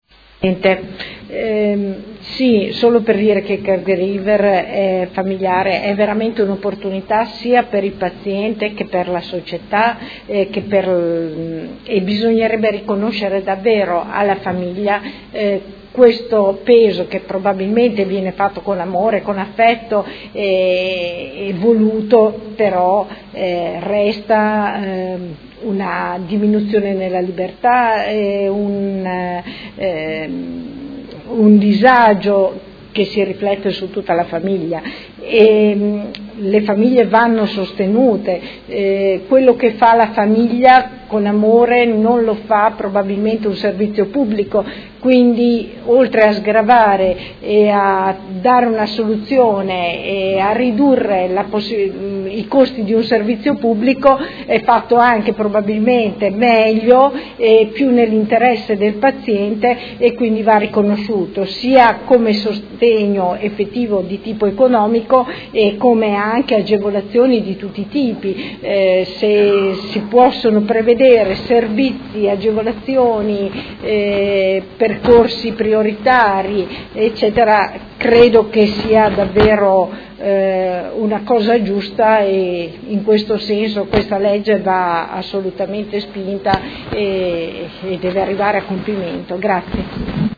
Seduta dell'11/05/2017 Dibattito su ordini del giorno sul "Caregiver familiare"